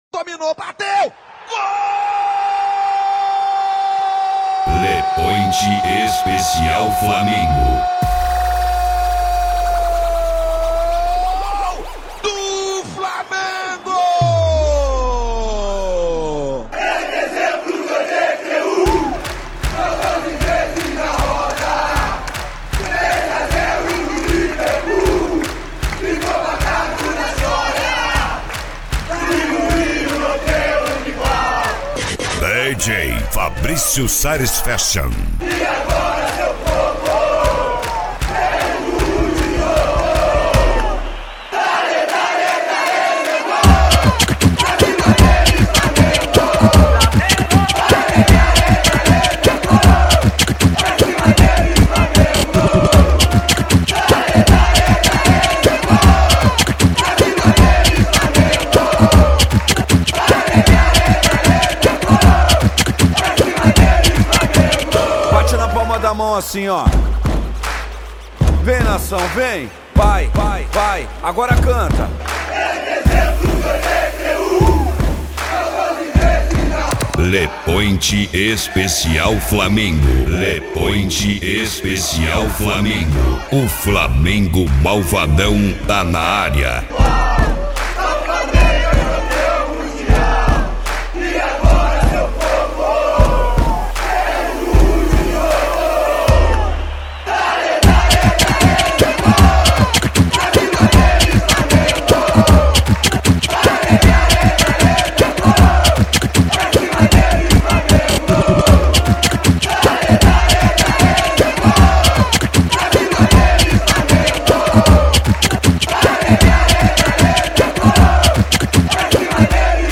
Funk
Mega Funk